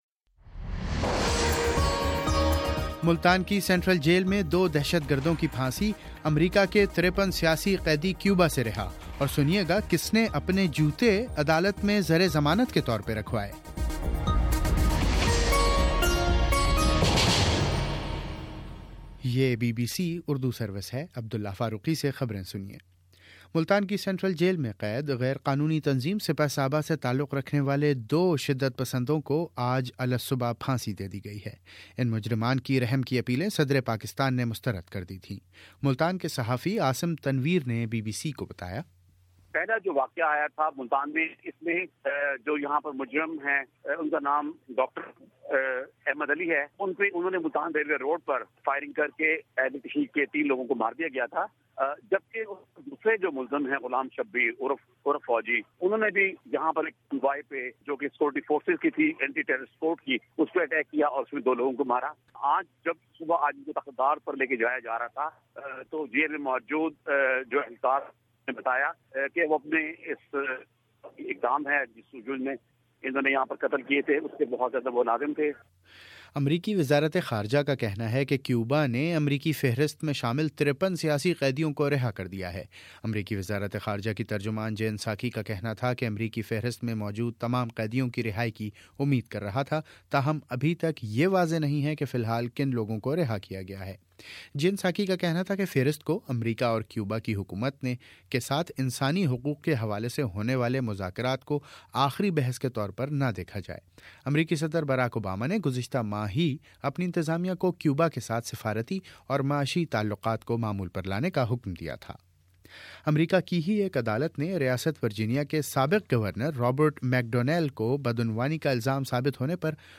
جنوری 07: شام چھ بجے کا نیوز بُلیٹن